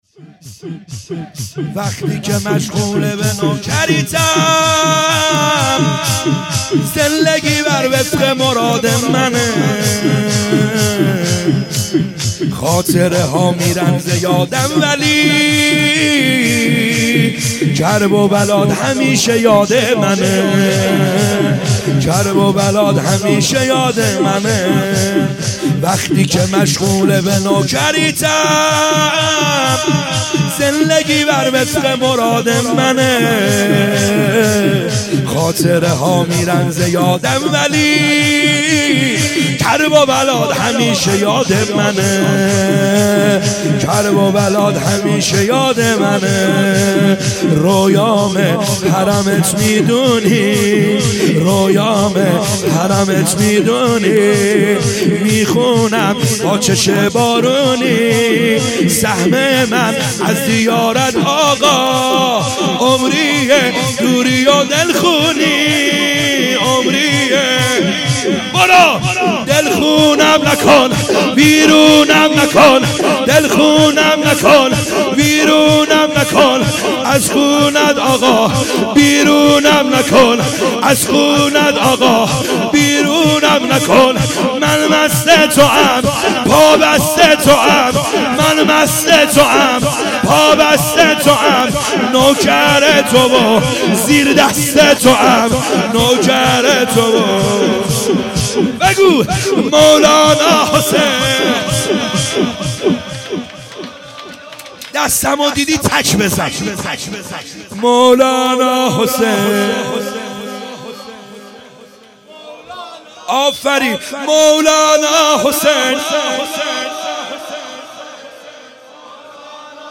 خیمه گاه - بیرق معظم محبین حضرت صاحب الزمان(عج) - شور | وقتی که مشغول به نوکریتم